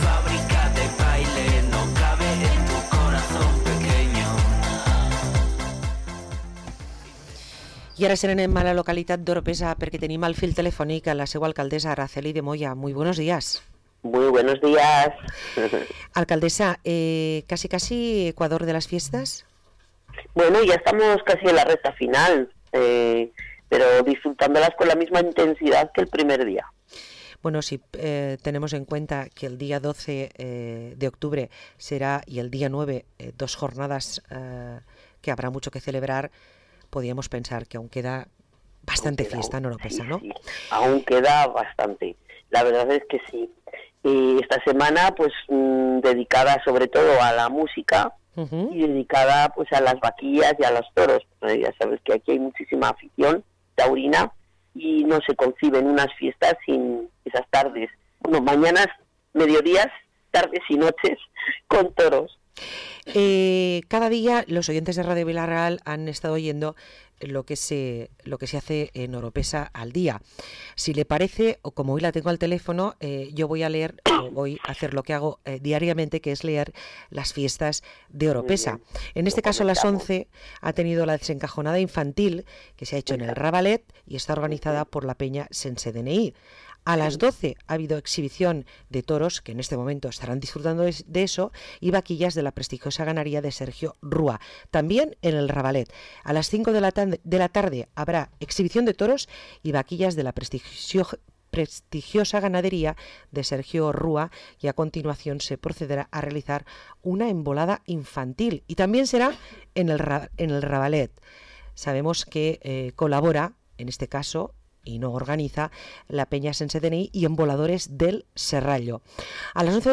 Parlem amb l’alcaldessa d´Orpesa, Araceli de Moya